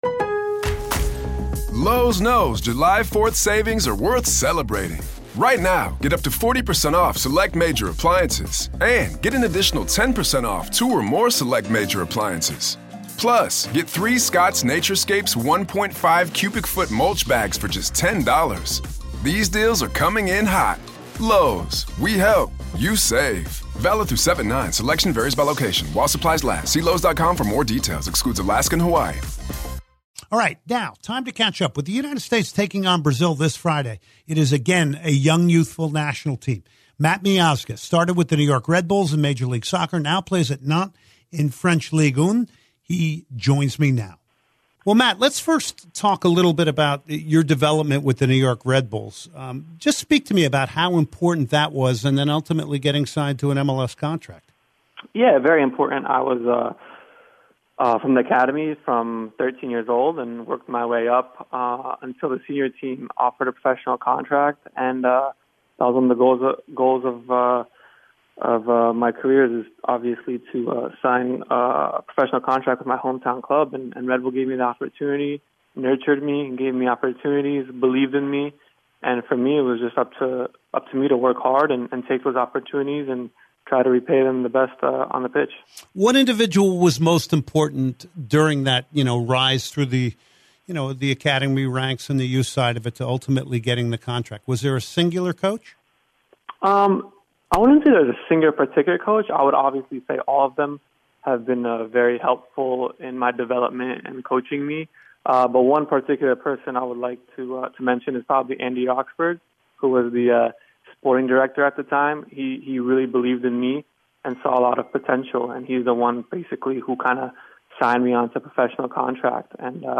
09/04/2018 Soccer Matters: Matt Miazga Interview